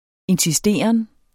Udtale [ ensiˈsdeˀʌn ]